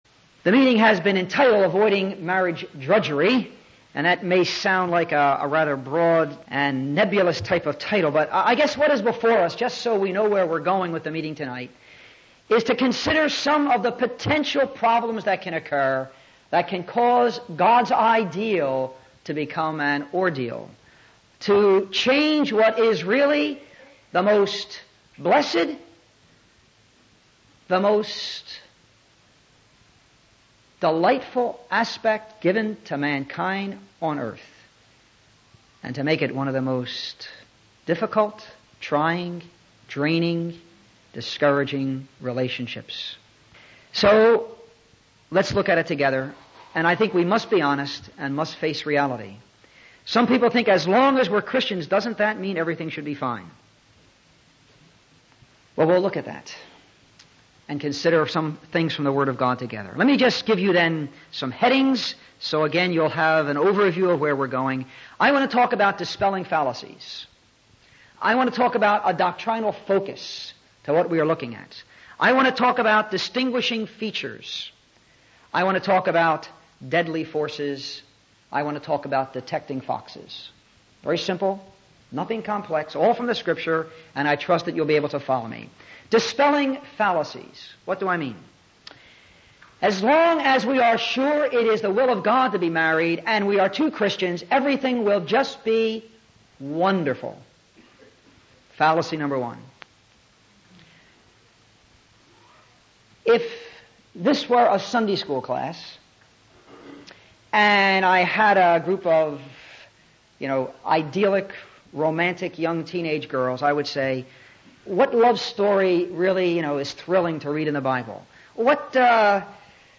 Message preached Nov 2002